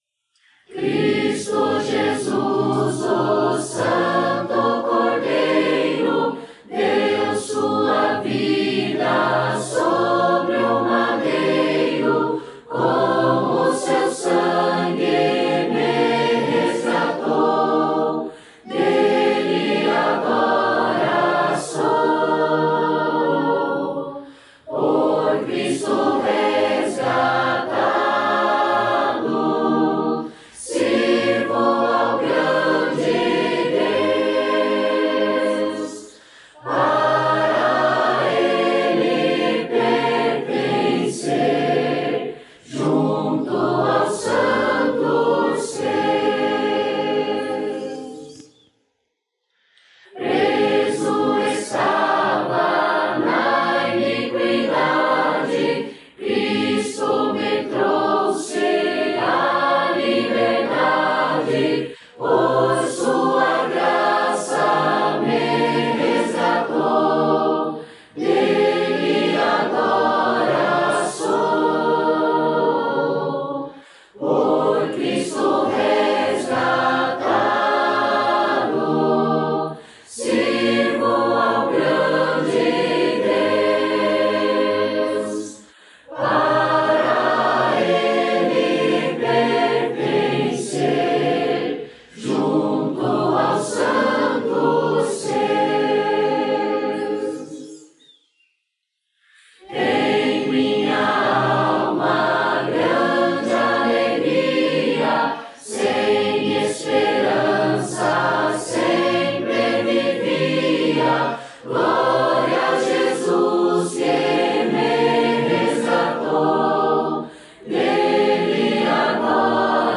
Hino: "Por Cristo, resgatado".